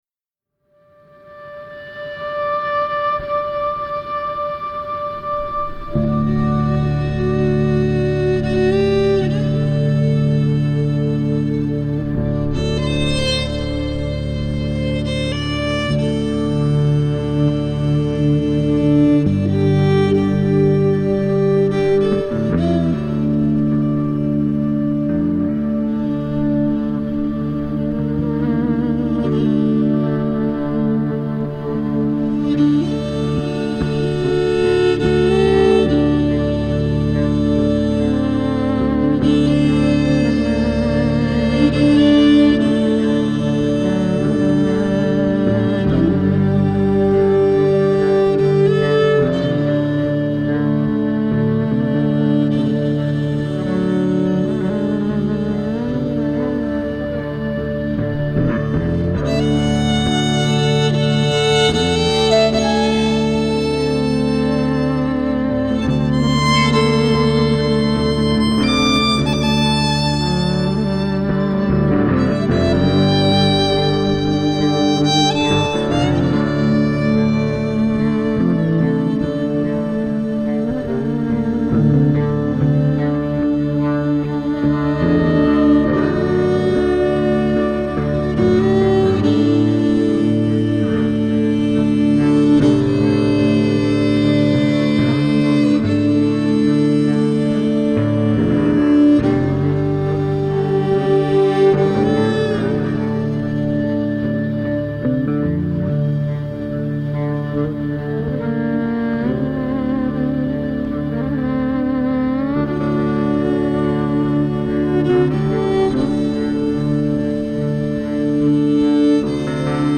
electric cello
slide guitar and violin... East meets West...